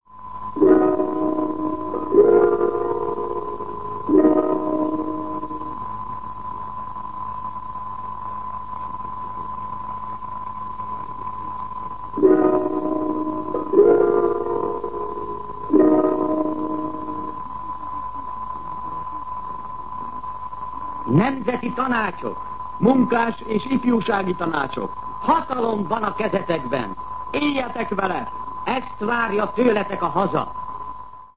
Jelmondatok 1956. november 01. 6:49 ● 00:31 ► Meghallgatom Műsor letöltése MP3 00:00 00:00 A műsor leirata Szünetjel Nemzeti Tanácsok!